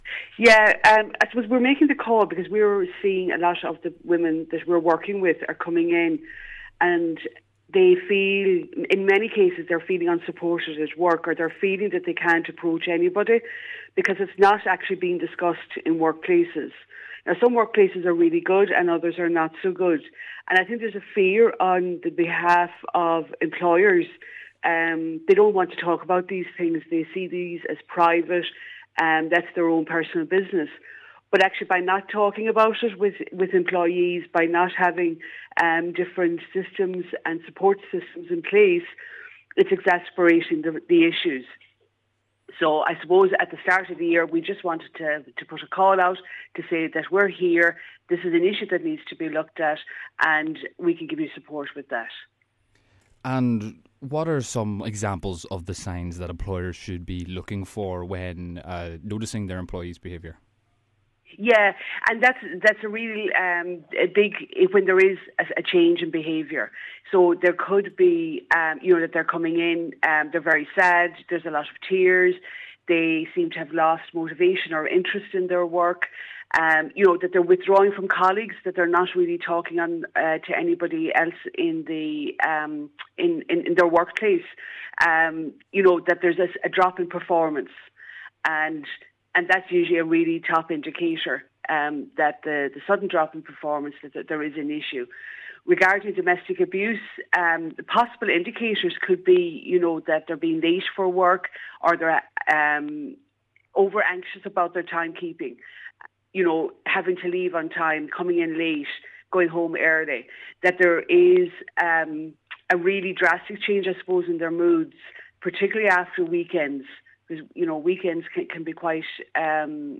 She has been explaining some of the signs employers could be taking notice of: